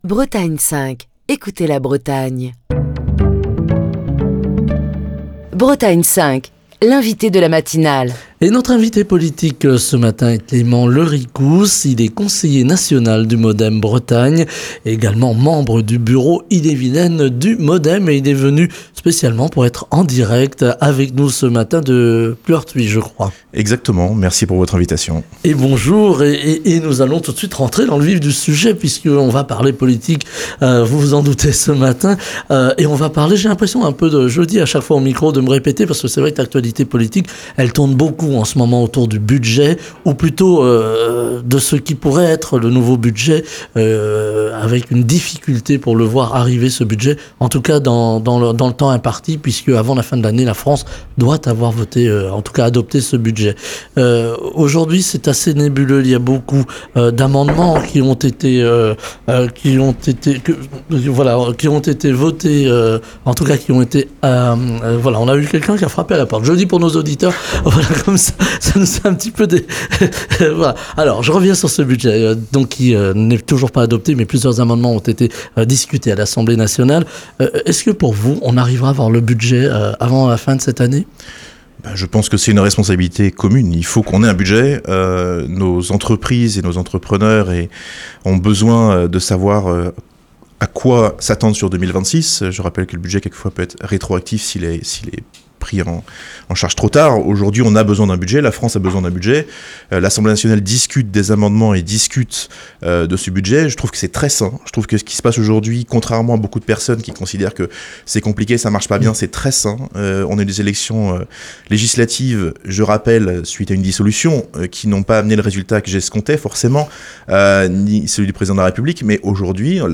Émission du 5 novembre 2025.